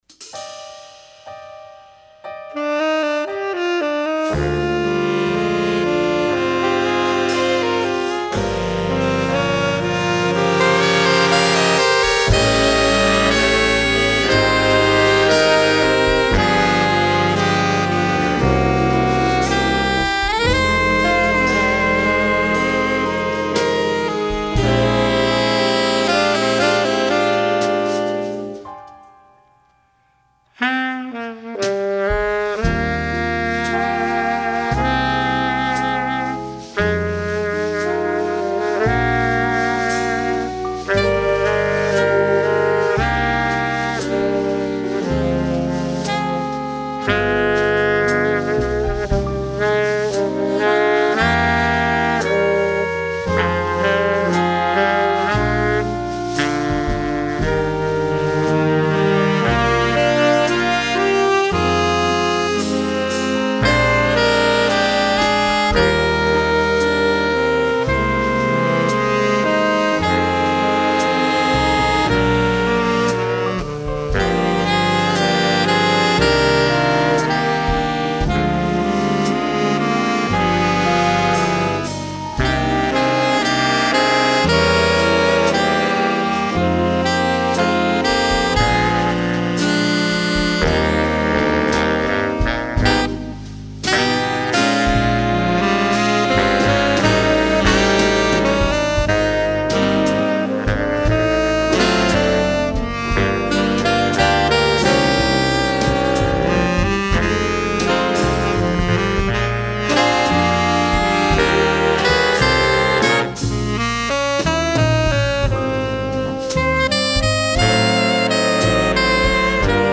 Voicing: Saxophone Section